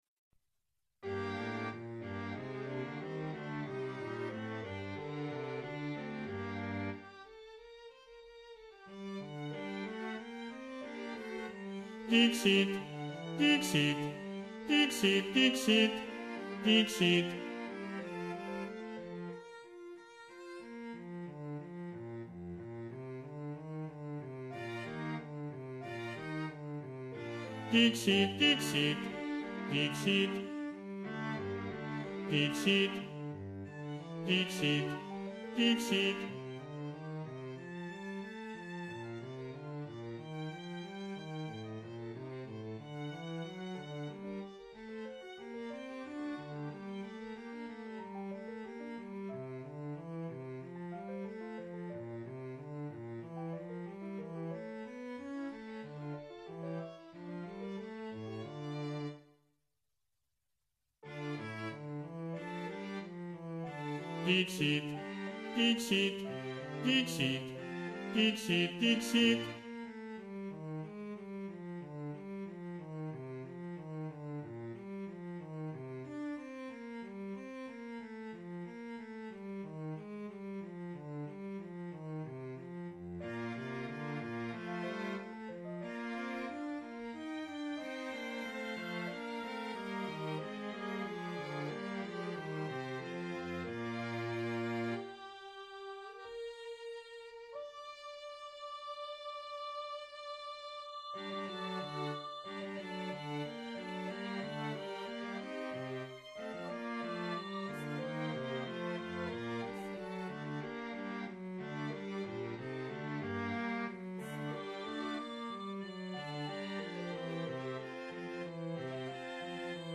Below you will find rehearsal recordings of the movements, arranged in the order they will be sung at the concerts on December 13 and 14, 2025.
Basses
Emphasised voice and other voices